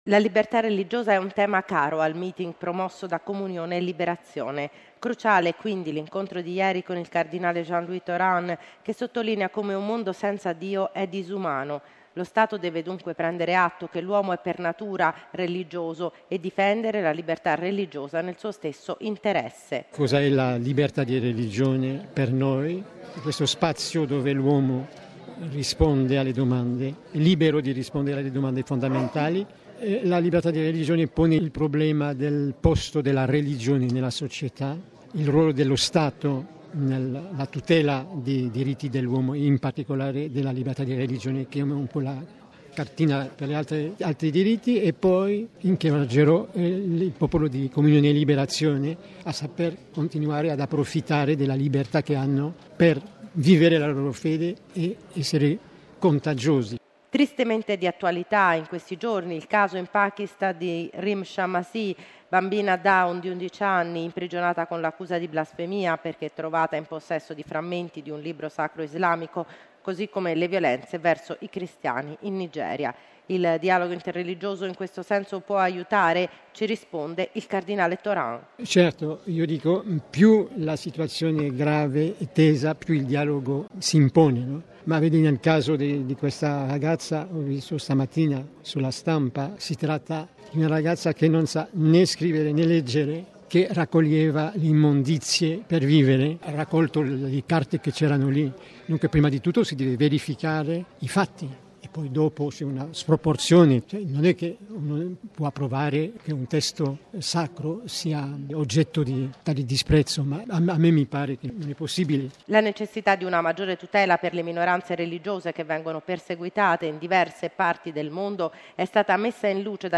Ci risponde il cardinale Tauran: